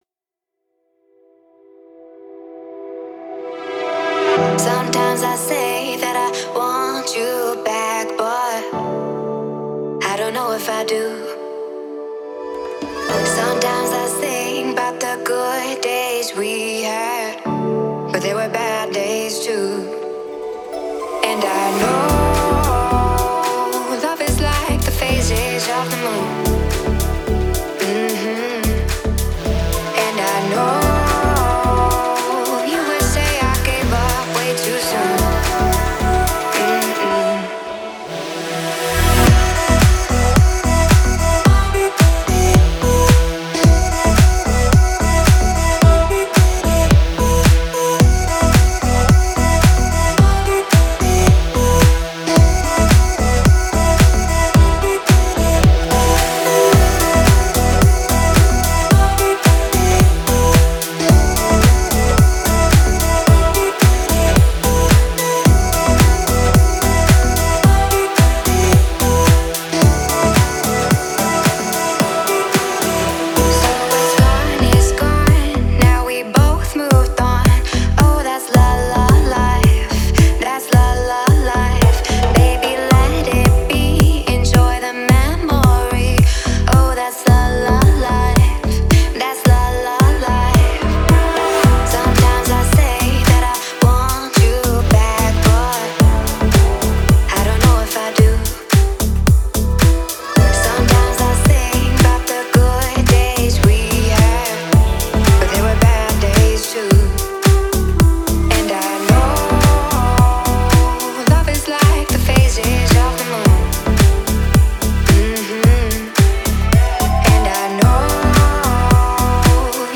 это трек в жанре прогрессив-хаус